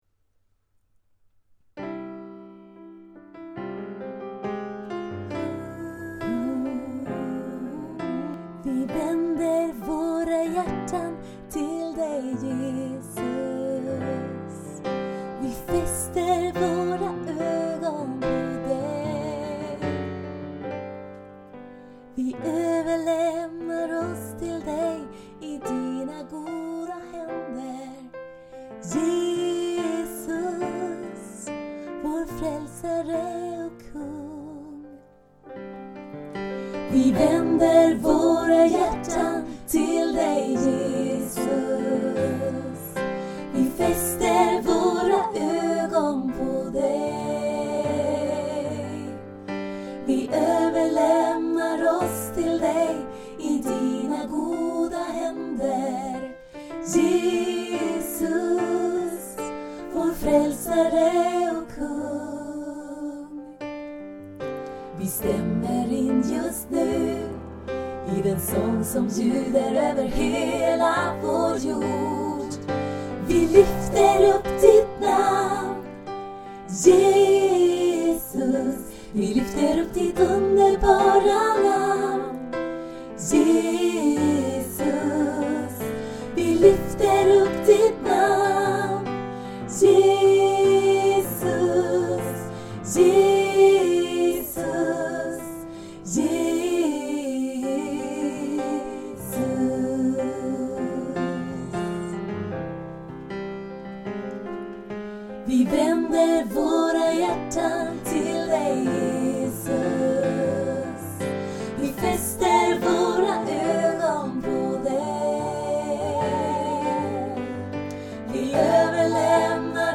Lovsånger